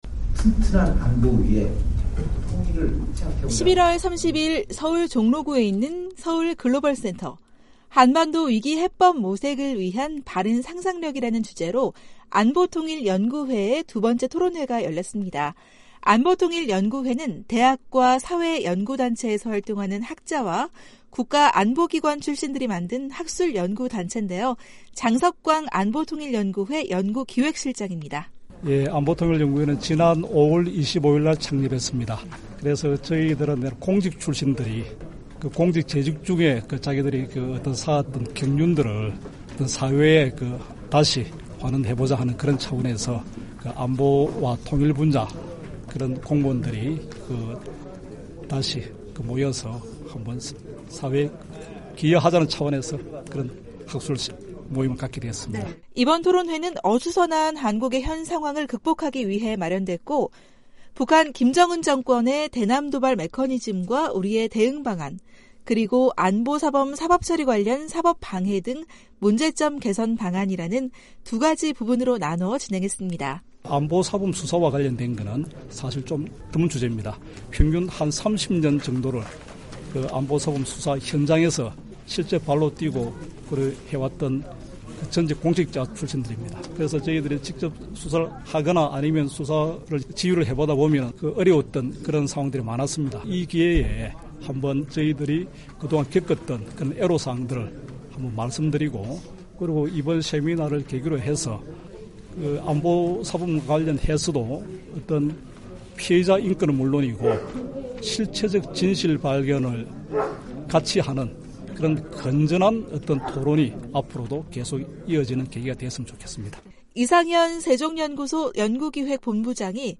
지난달 30일 서울 종로구 글로벌센터에서 '한반도 위기 해법 모색을 위한 바른 상상력‘ 이라는 주제로 안보통일구회의의 토론회가 열렸다.